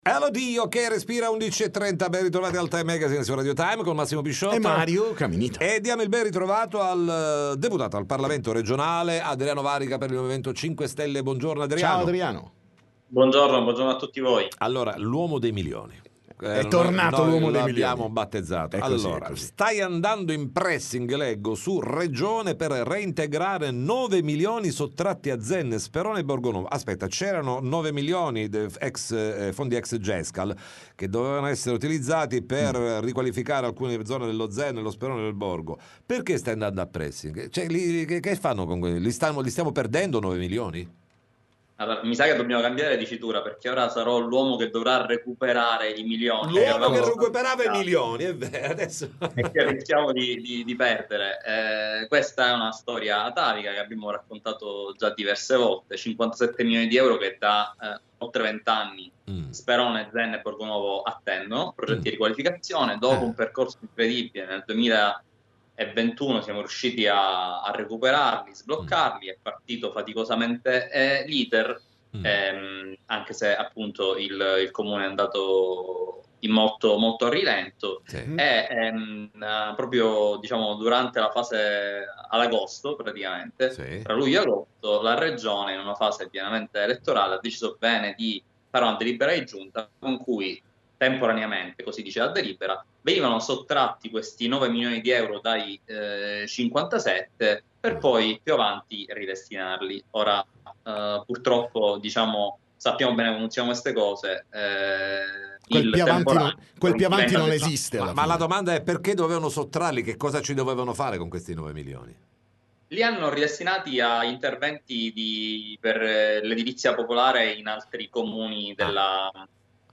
TM Intervista Adriano Varrica